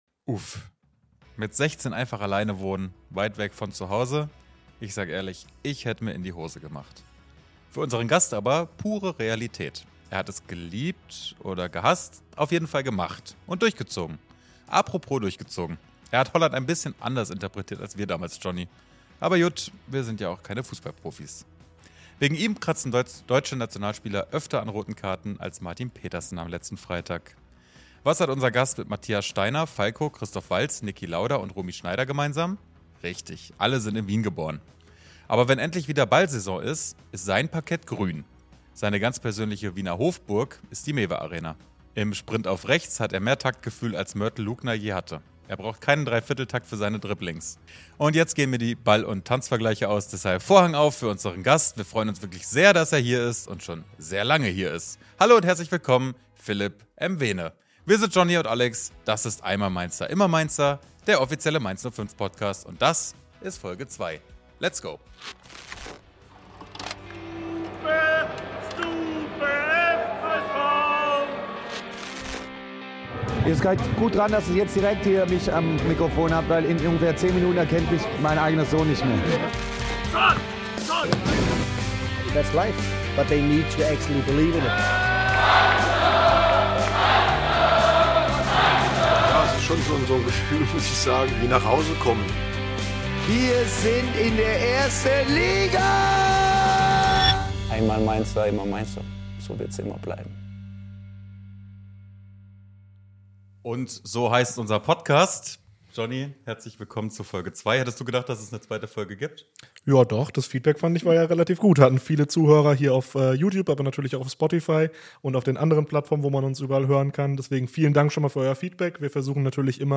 Beschreibung vor 1 Jahr Nen Hund im Studio?!